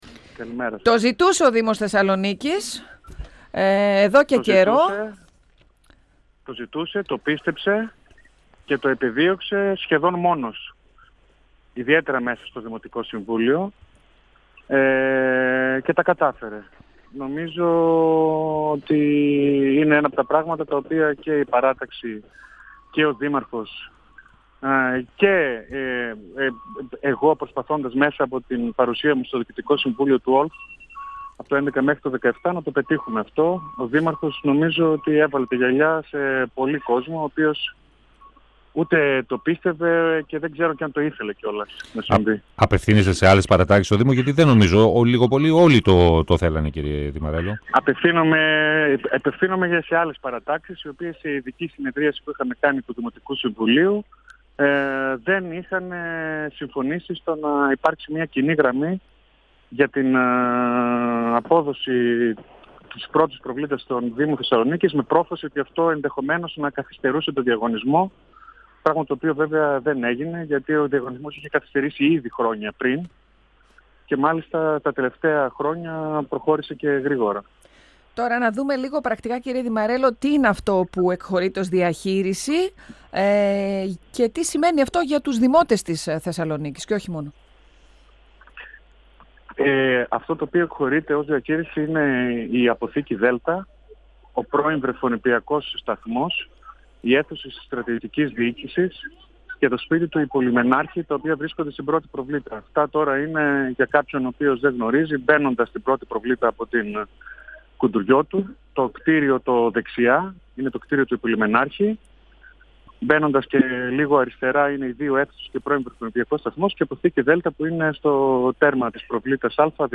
O  αντιδήμαρχος Αστικής Ανθεκτικότητας Γιώργος Δημαρέλλος στον 102FM του Ρ.Σ.Μ. της ΕΡΤ3